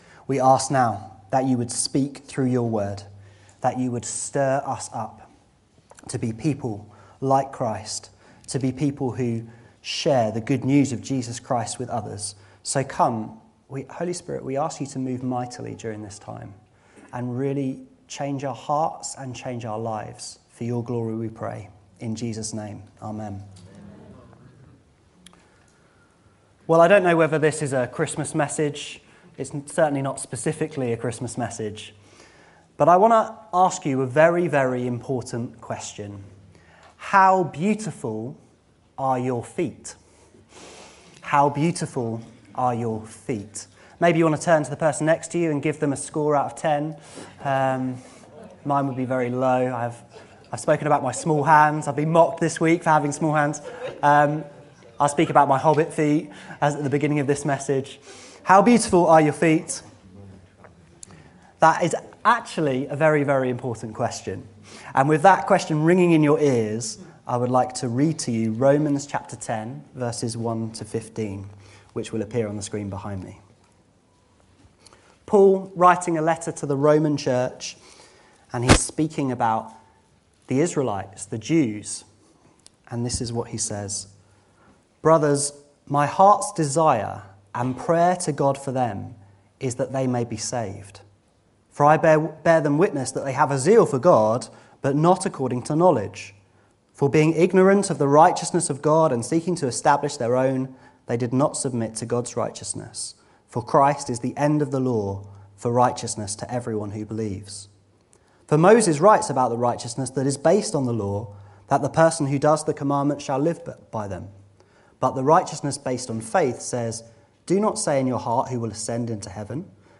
This sermon is a call to ALL Christians to be preachers of the good news!